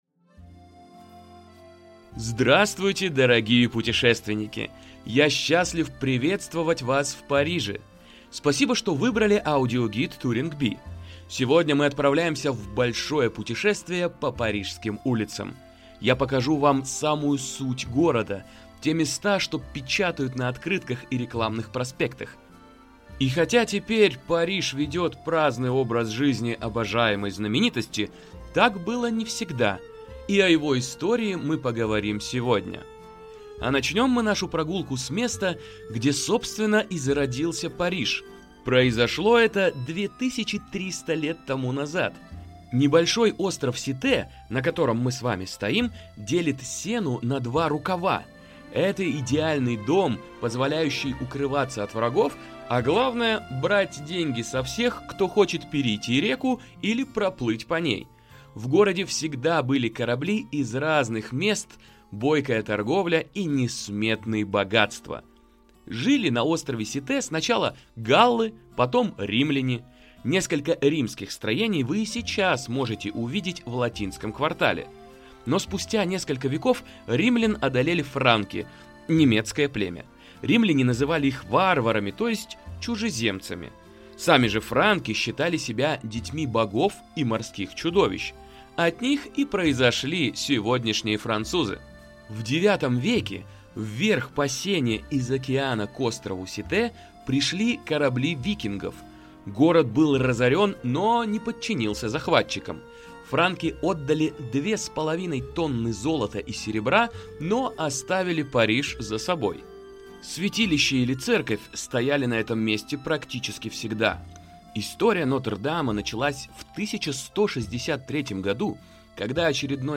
Аудиогид по Парижу: слушай, гуляй и влюбляйся с TouringBee
Здесь вам не надо разглядывать карту и ссориться с навигатором – голос в наушниках подскажет, куда свернуть и где замедлиться.